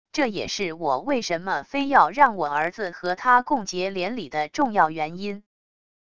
这也是我为什么非要让我儿子和她共结连理的重要原因wav音频生成系统WAV Audio Player